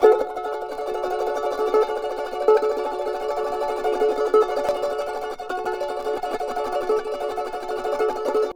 CHAR D#MJ TR.wav